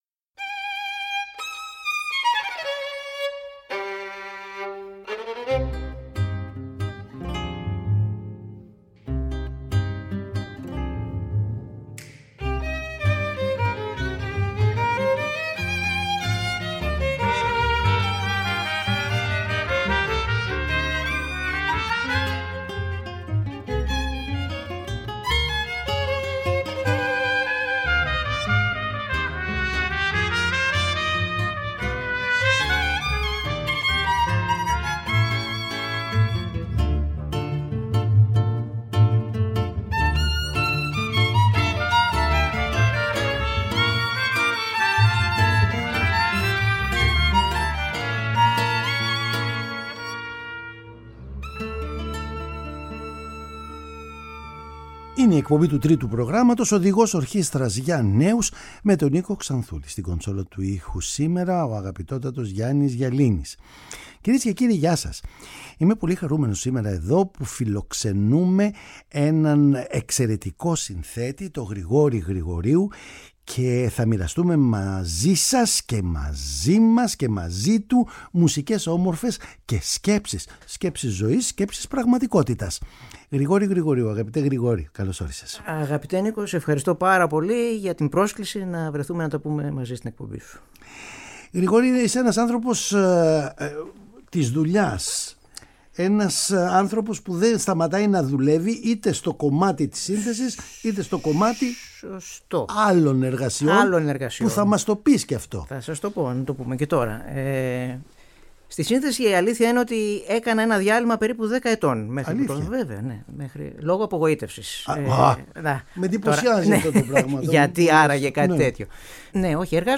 Η ενασχόλησή του τόσο με την μουσική όσο και με τις επιχειρήσεις είναι ένα θέμα που αξίζει να το συζητήσει κανείς μαζί του συνδυαστικά και να αποτελέσει μια ευκαιρία να ακούσουμε όμορφες συνθέσεις του
Παραγωγή-Παρουσίαση: Νίκος Ξανθούλης